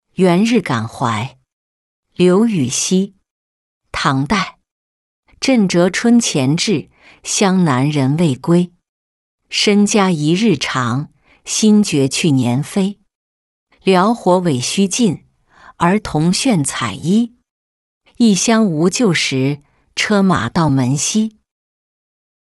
元日感怀-音频朗读